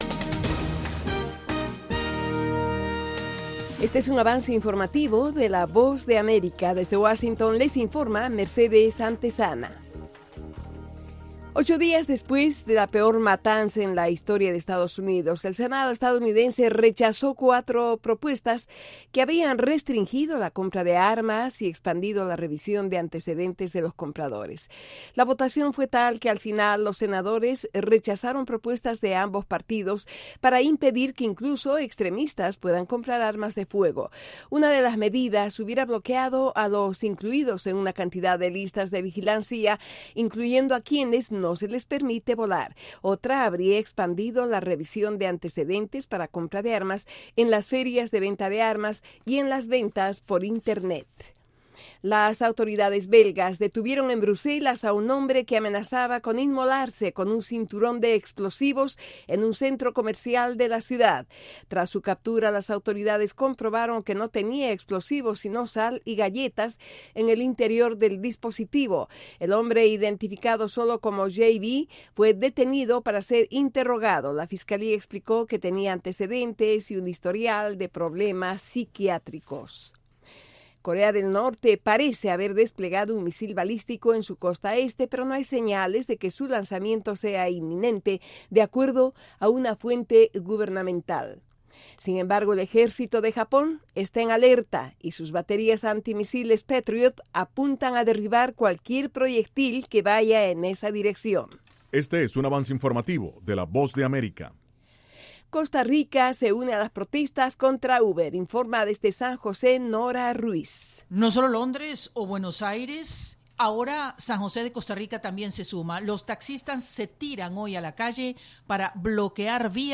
Avance Informativo - 10:00am